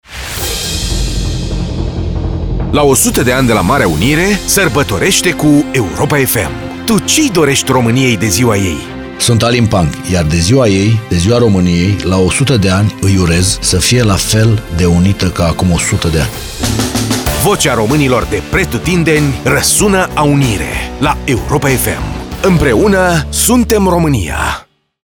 De 1 Decembrie, actorul Alin Panc transmite, la Europa FM, un mesaj de unitate tuturor românilor.